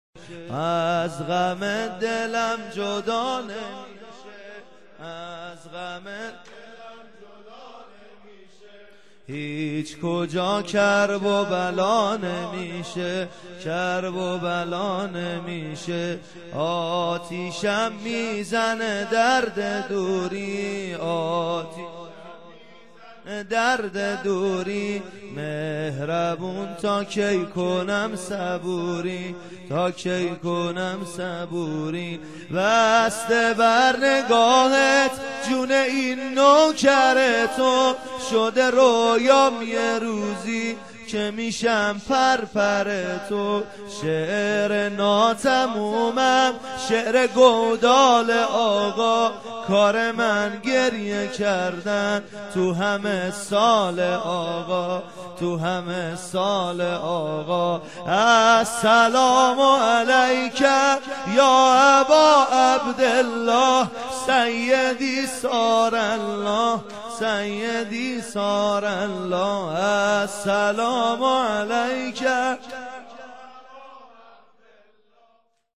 نوحه واحد به همراه سبک برای امام حسن (ع) ( تا حسن امام مهربونه)